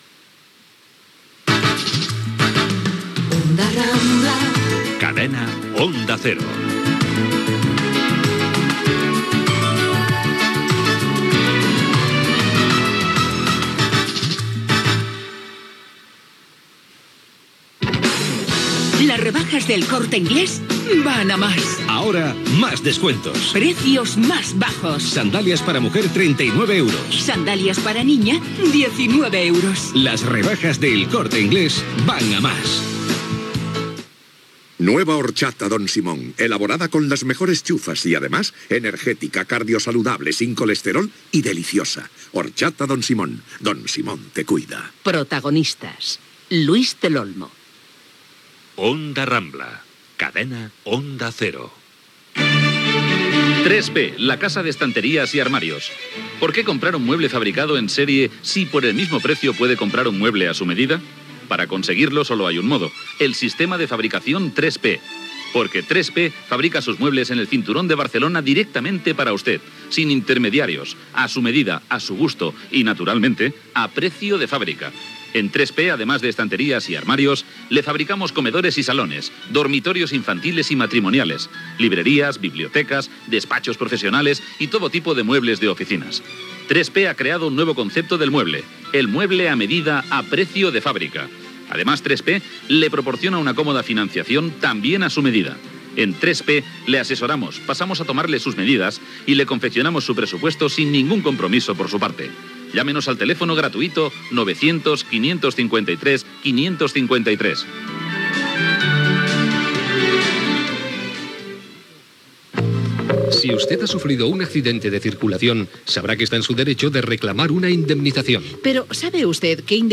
Indicatiu, publicitat, cançó del programa, paraules de comiat del programa després d'haver-se emès 13 anys a Onda Cero, cançó del programa, publicitat
Info-entreteniment